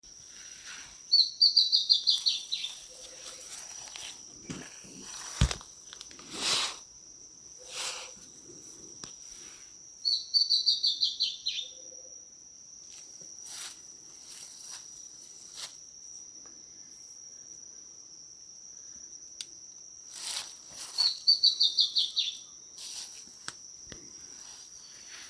Bertoni´s Antbird (Drymophila rubricollis)
Province / Department: Misiones
Location or protected area: Parque Provincial Cruce Caballero
Condition: Wild
Certainty: Observed, Recorded vocal